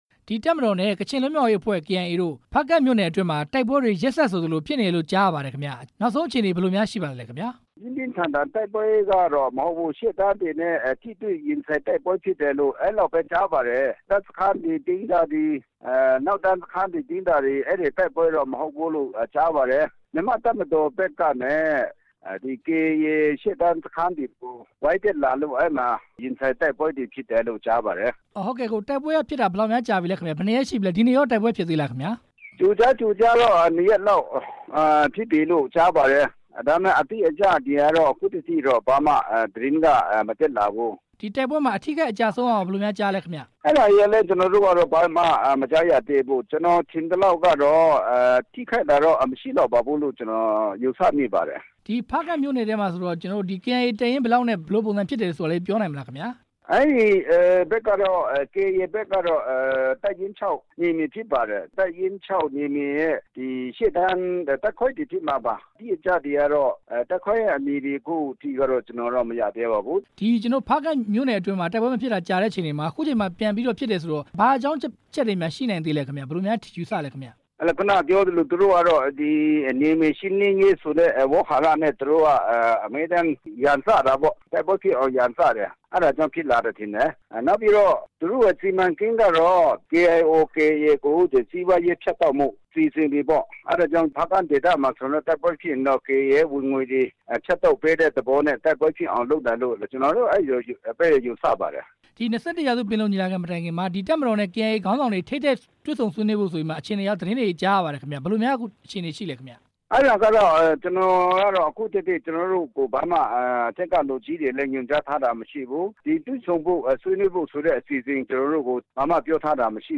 ကချင်ပြည်နယ် ဖားကန့်မြို့နယ် အတွင်း တိုက်ပွဲ အကြောင်း မေးမြန်းချက်